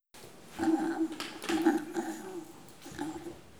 ajout des sons enregistrés à l'afk